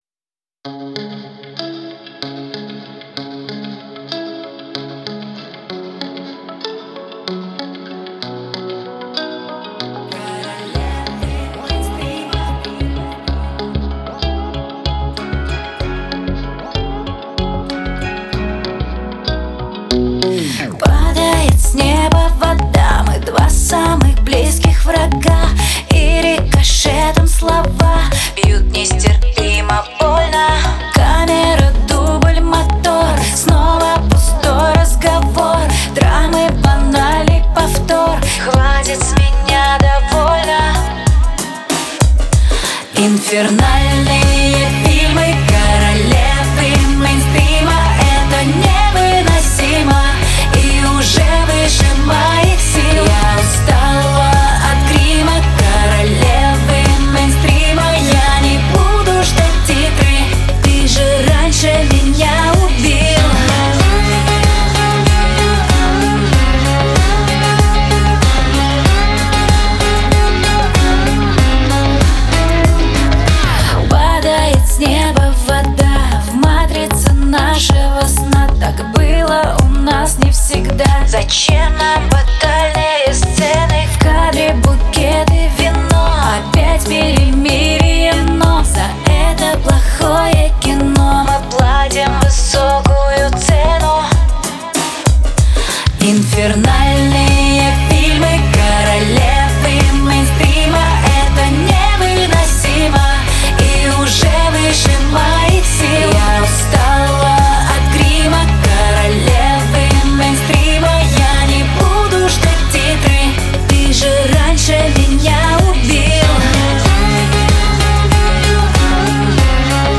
это яркая и запоминающаяся композиция в жанре поп